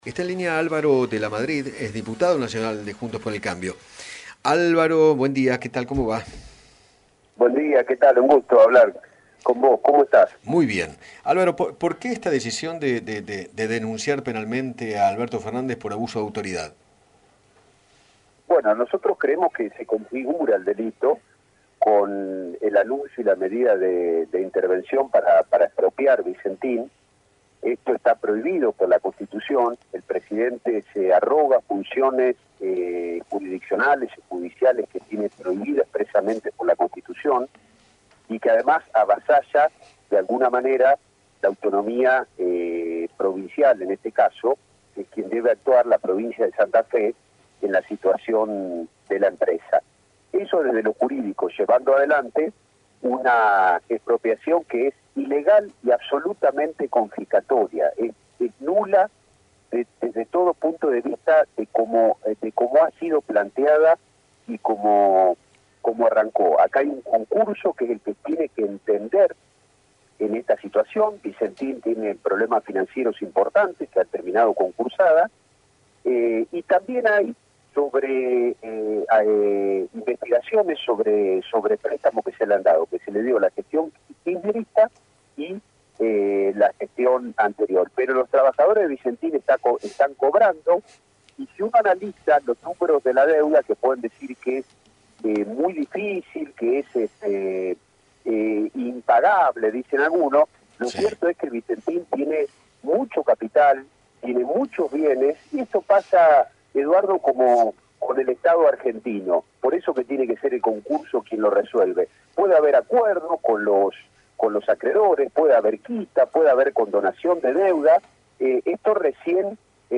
Álvaro de Lamadrid, diputado Nacional, dialogó con Eduardo Feinmann sobre el proyecto del Gobierno de intervenir Vicentín y sostuvo que “el objetivo es quedarse con la matriz productiva y alimentos de los argentinos”.